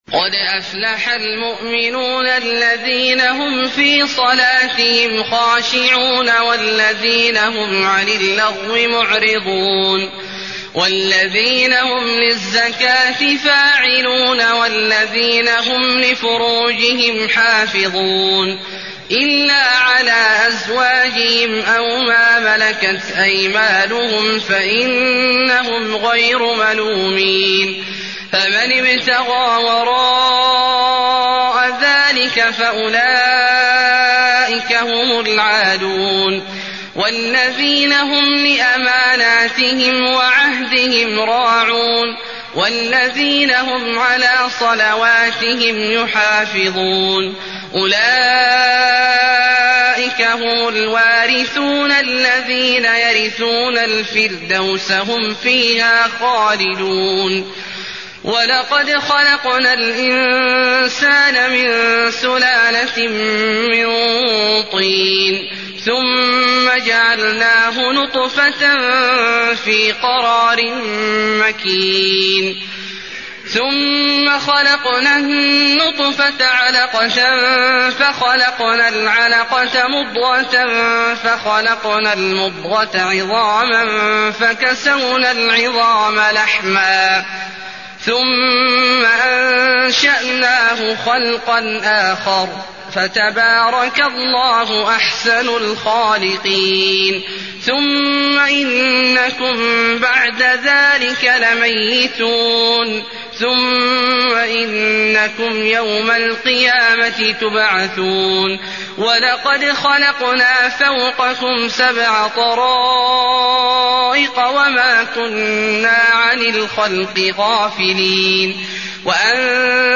المكان: المسجد النبوي المؤمنون